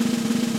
soft-sliderslide.ogg